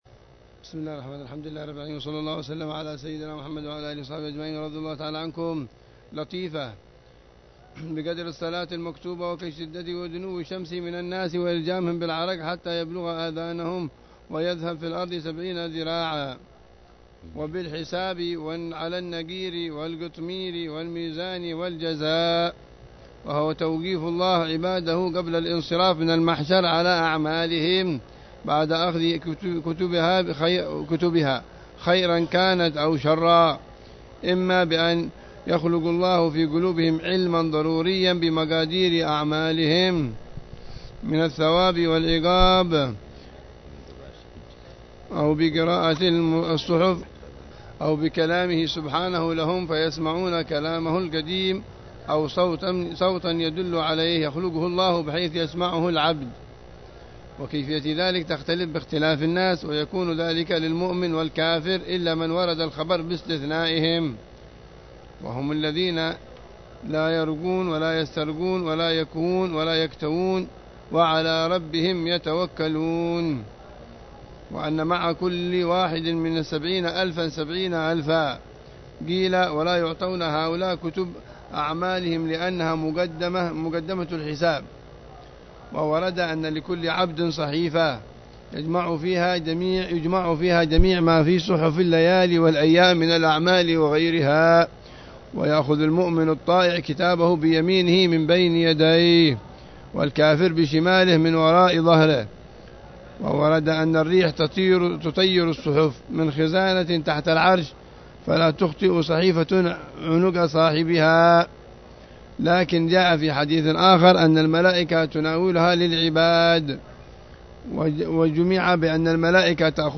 الدرس الخامس والثلاثون من دروس شرح الحبيب عمر بن حفيظ على كتاب كفاية الراغب شرح هداية الطالب إلى معرفة الواجب للإمام العلامة عبد الله بن الحس